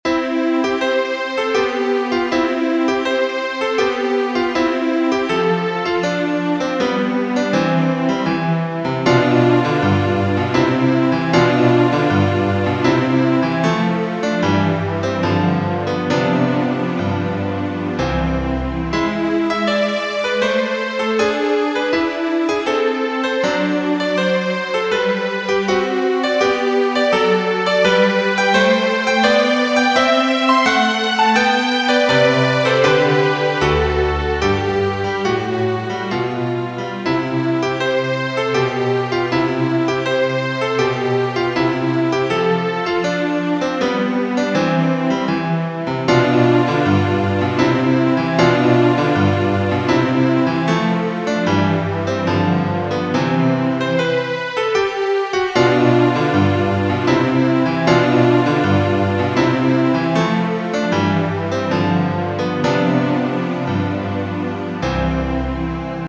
第2楽章は付点のリズムがとぎれなく流れる。